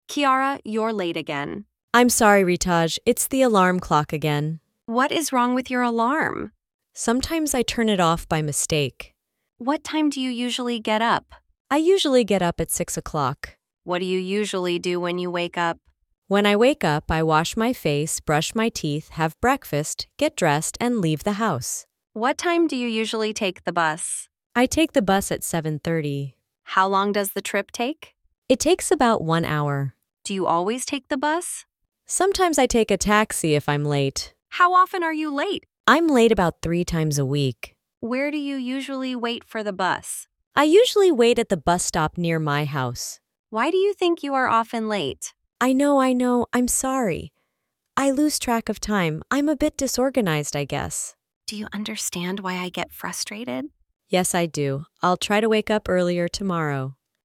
View Full Library Late Again!In this lesson, students practice Present Simple information questions through a natural conversation about being late.